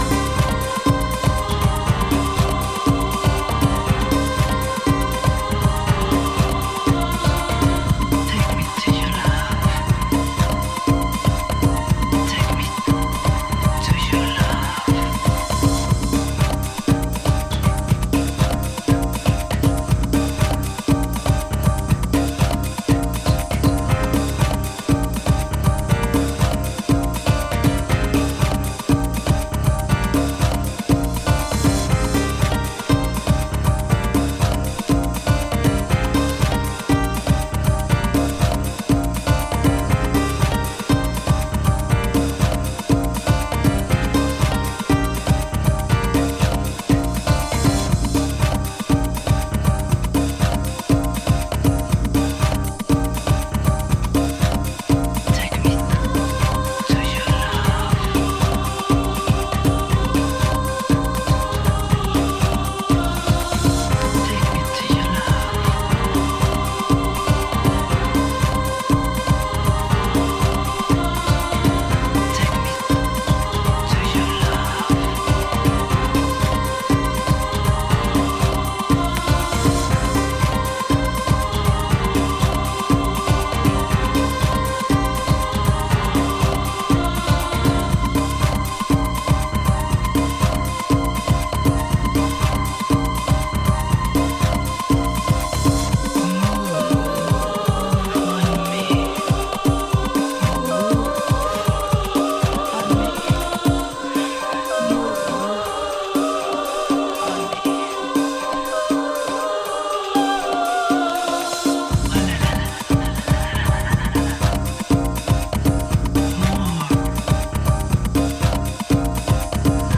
ジャンル(スタイル) DEEP HOUSE / CLASSIC HOUSE / BALEARIC HOUSE